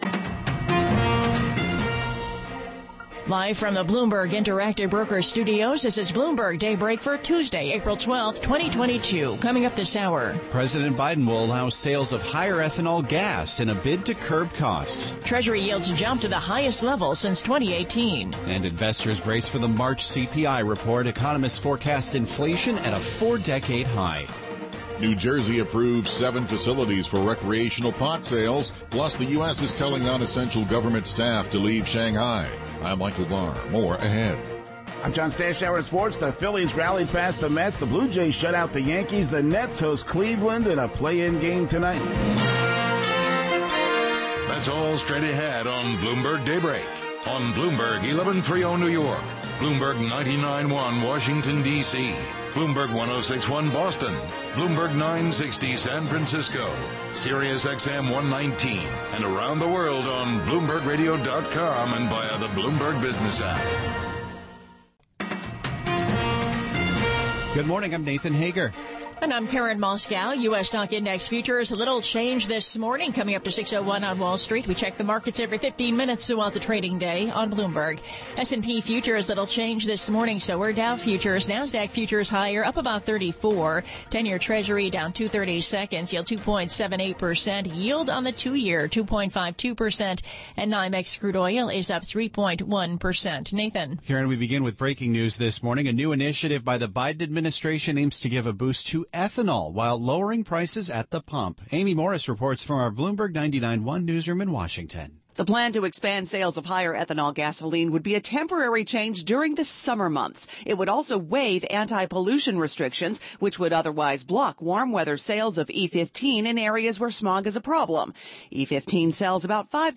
Bloomberg Daybreak: April 12, 2022 - Hour 2 (Radio) - Transcript and Chapters - from Podcast Bloomberg Daybreak: US Edition